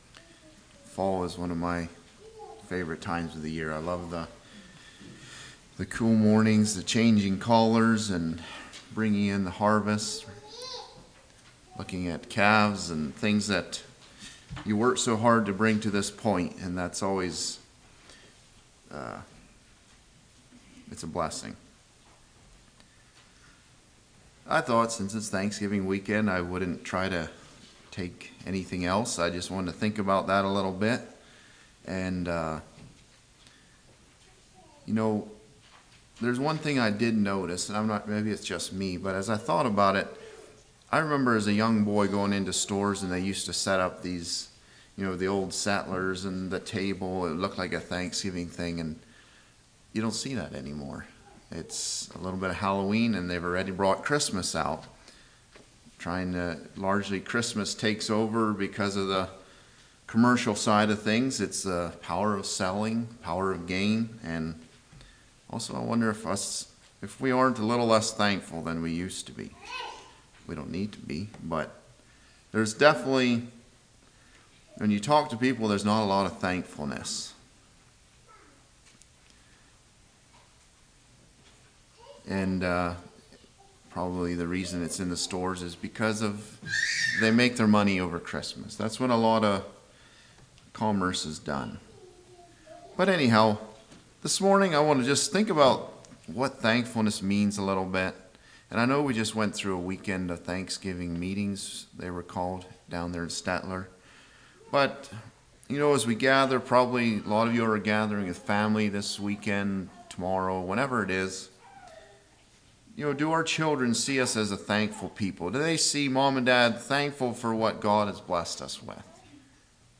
Sermons 10.06.24 Play Now Download to Device Thanksgiving Congregation